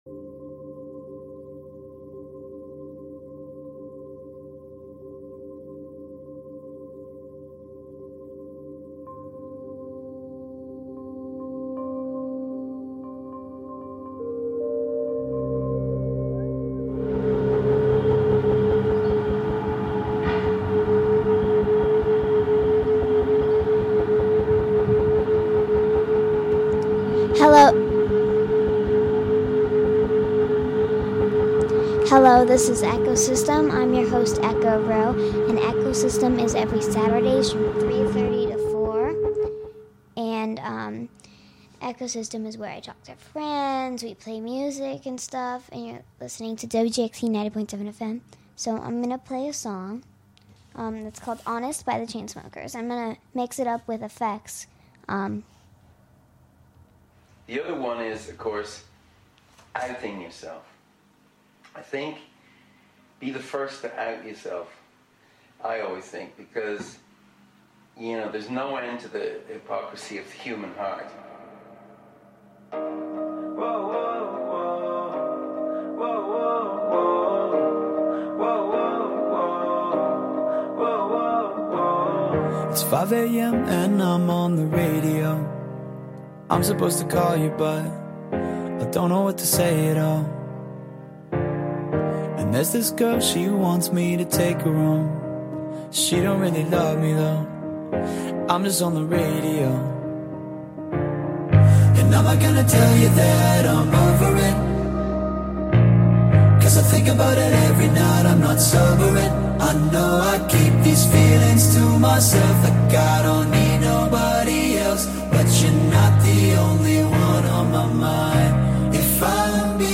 EchoSystem is a weekly youth program where friends come together to talk, learn, and experiment with sound, music, and radio.